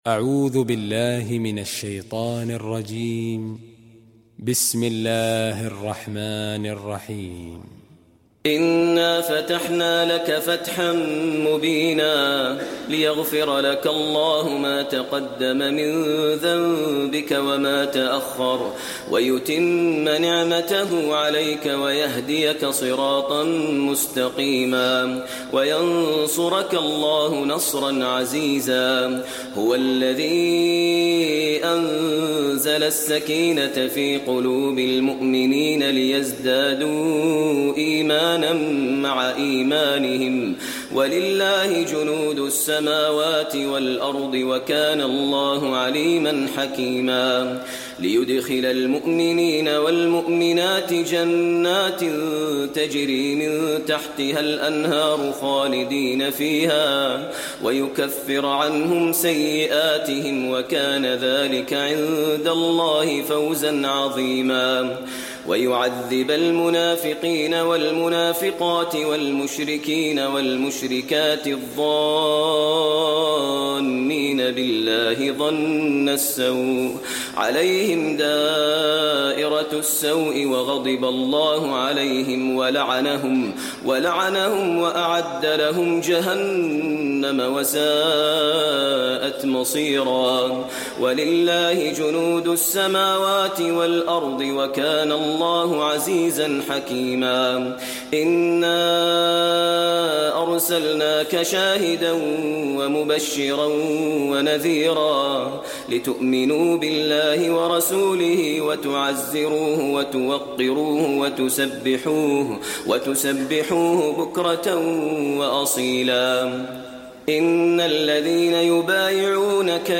المكان: المسجد النبوي الفتح